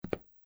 普通的行走脚步单生－右声道－YS070525.mp3
通用动作/01人物/01移动状态/普通的行走脚步单生－右声道－YS070525.mp3